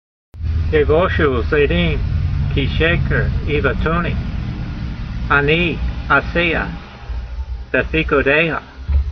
v78_voice.mp3